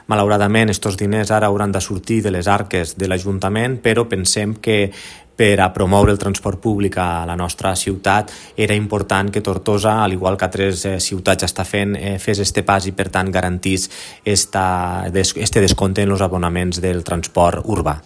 L’alcalde de Tortosa, Jordi Jordan, ha afirmat que la mesura respon a la necessitat de promoure l’ús del transport públic al municipi…